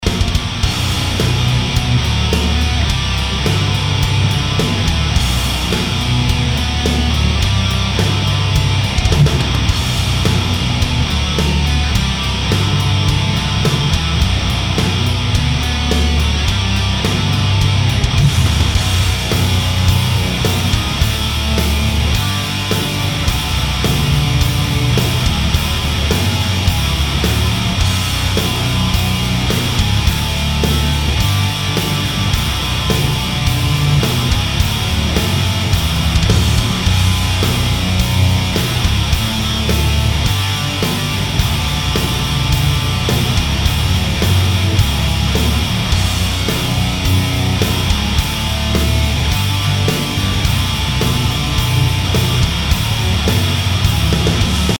Boss HM-2 - Peavey Rockmaster - RedWirez T75